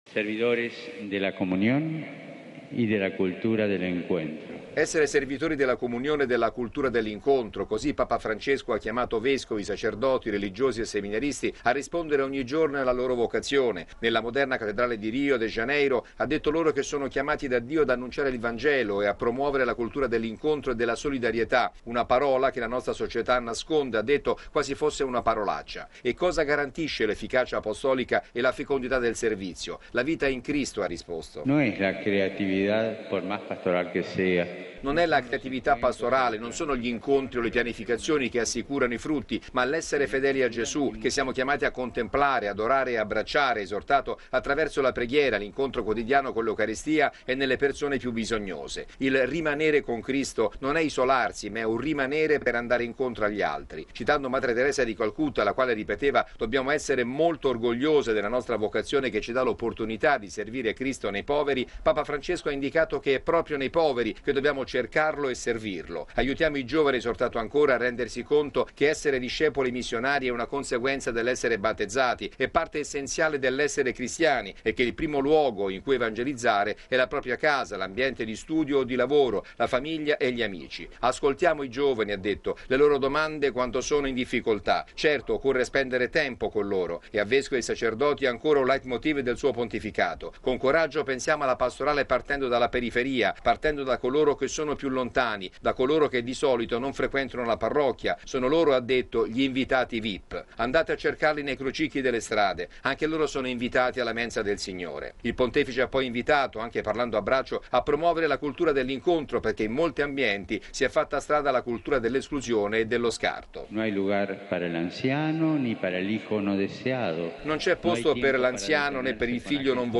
◊   Dobbiamo uscire dalle parrocchie per annunciare Cristo senza presunzioni. Lo ha affermato Papa Francesco nella Messa presieduta questa mattina nella cattedrale di Rio de Janeiro, concelebrata con i vescovi, i sacerdoti e i religiosi presenti alla Gmg.